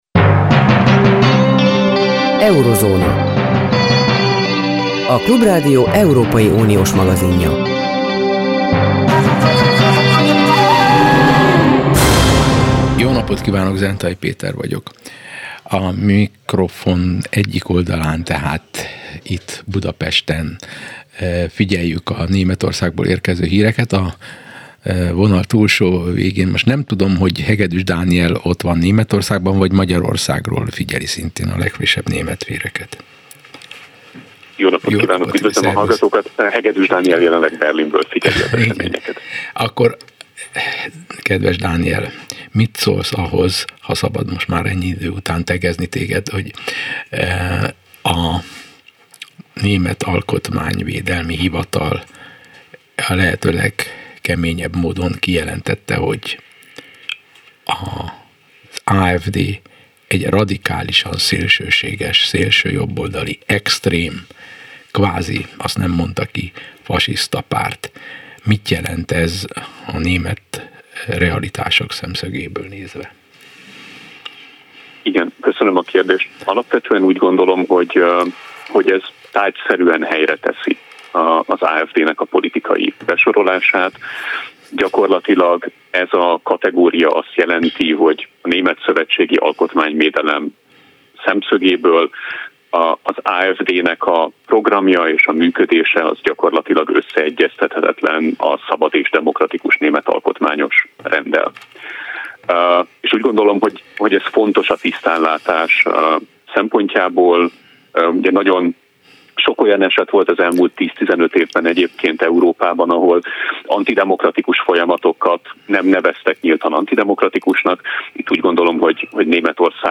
A Klubrádió külpolitikai magazinműsora. Állandó témáink az Európai Unió, benne Magyarország jelene és jövője, valamint a nagyvilág politikai, gazdasági, és társadalmi folyamatai.